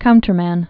(kountər-măn, -mən)